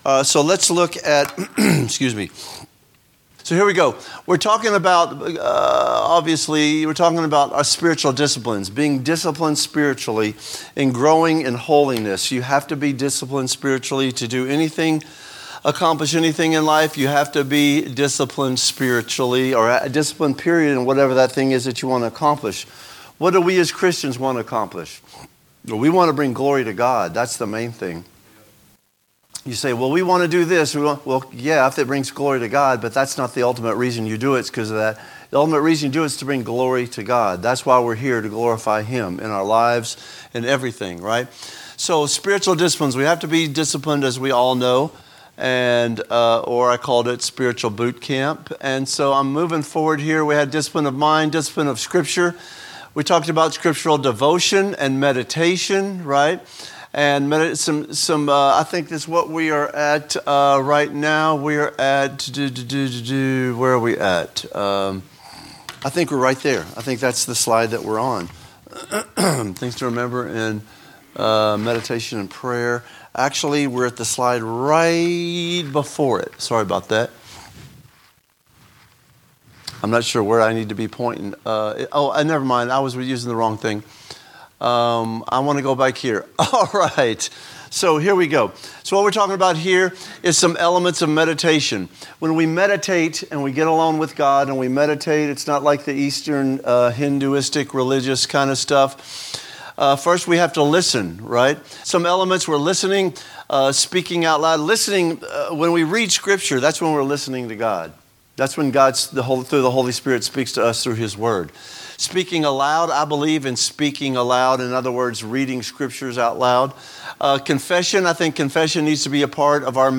A message from the series "General Teaching."